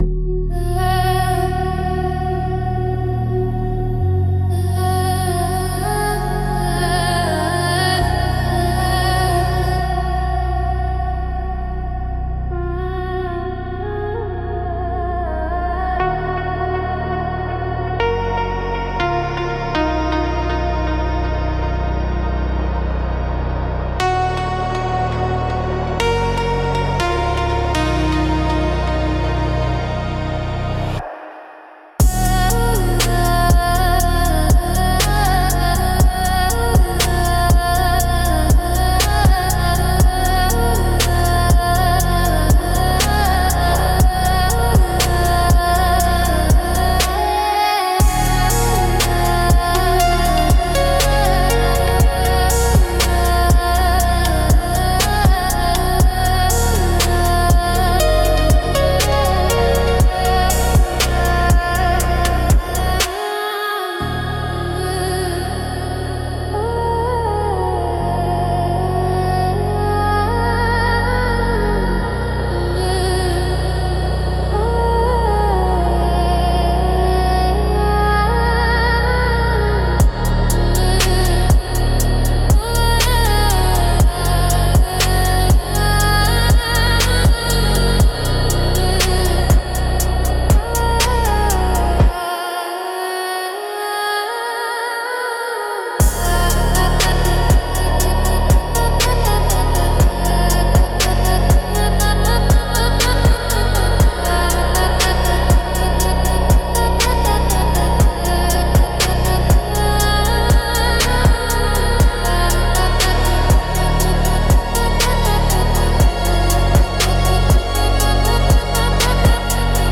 Instrumental - Beneath the Surface Tension 3.23